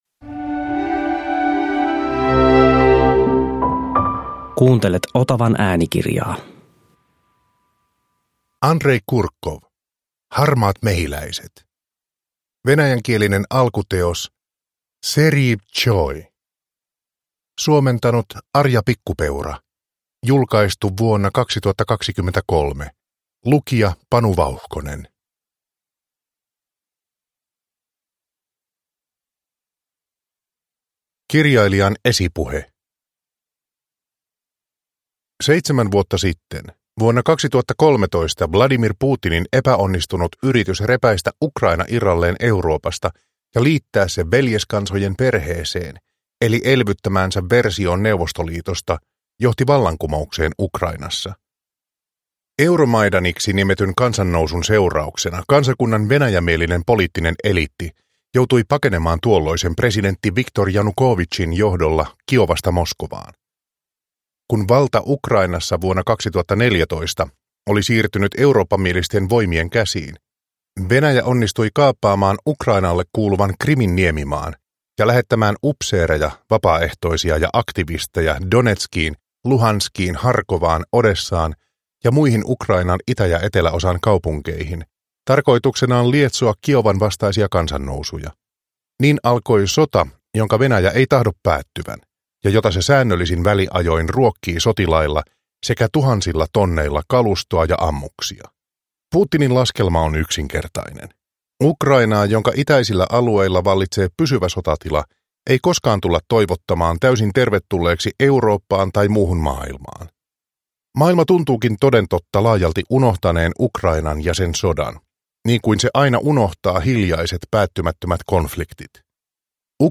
Harmaat mehiläiset – Ljudbok – Laddas ner